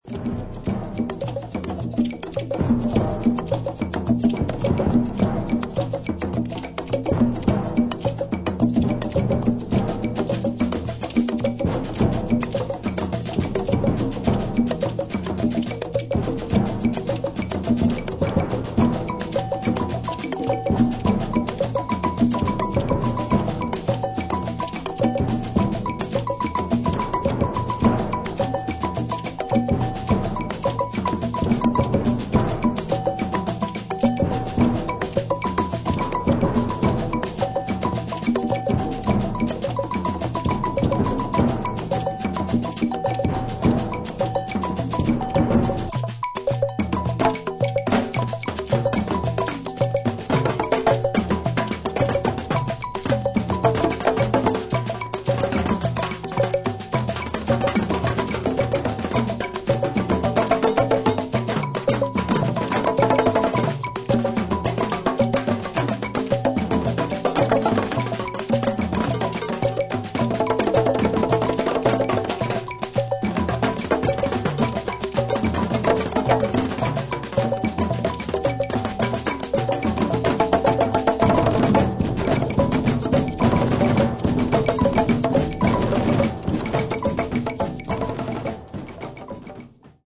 トライバリズムを追求したような